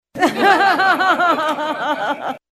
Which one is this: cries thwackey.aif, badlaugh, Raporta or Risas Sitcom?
badlaugh